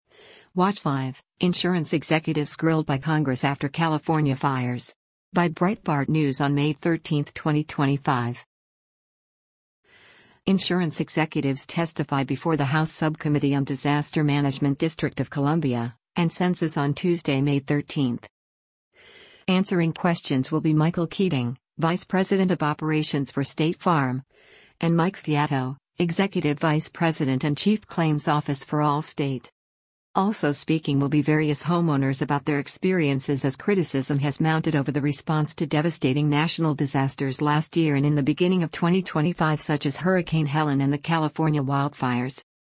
Insurance executives testify before the House Subcommittee on Disaster Management, District of Columbia, and Census on Tuesday, May 13.